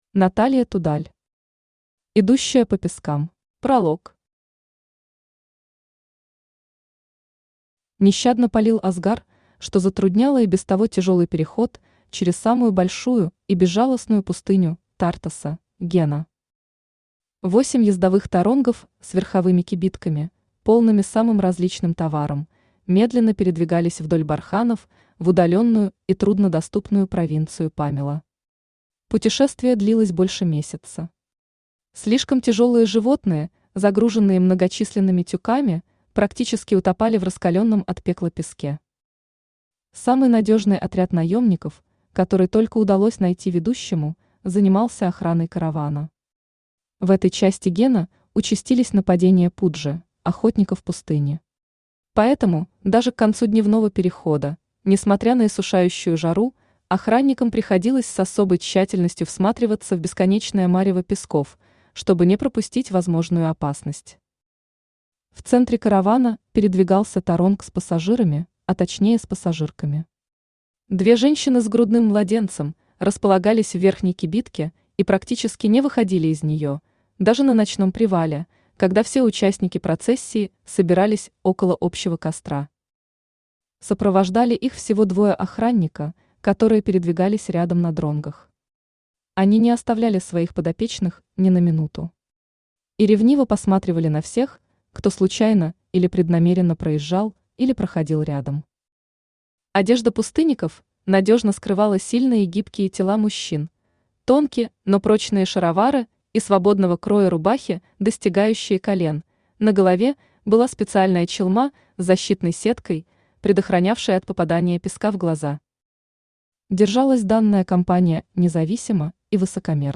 Aудиокнига Идущая по пескам Автор Наталья Тудаль Читает аудиокнигу Авточтец ЛитРес.